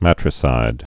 (mătrĭ-sīd)